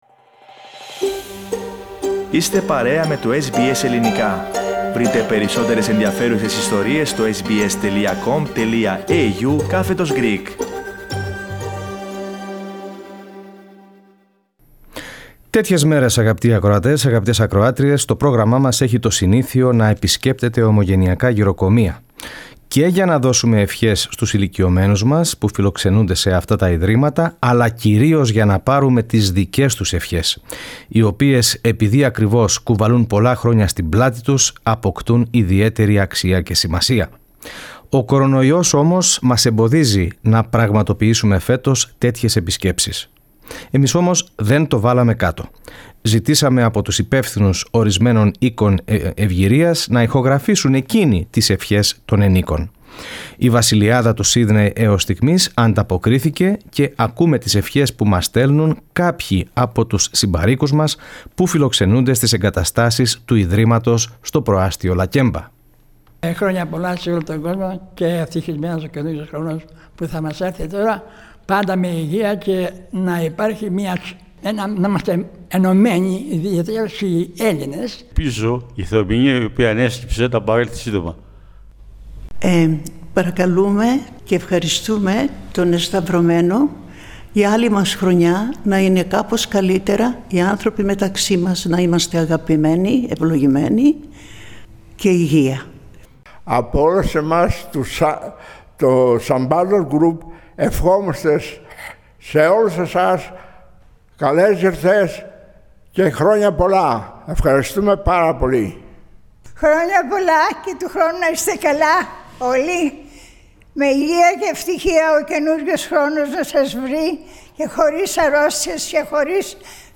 Ευχές και καλά Χριστούγεννα από ηλικιωμένους στη Βασιλειάδα
Συμπάροικοι από τη μονάδα της Βασιλειάδας στο Lakemba του Σύνδεϋ εύχονται καλά Χριστούγεννα στην Ελληνική παροικία.
Το πρόγραμμα μας ζήτησε από ορισμένα γηροκομεία να μας στείλουν ηχογραφημένες ευχές ηλικιωμένων ομογενών και εκείνα ανταποκρίθηκαν.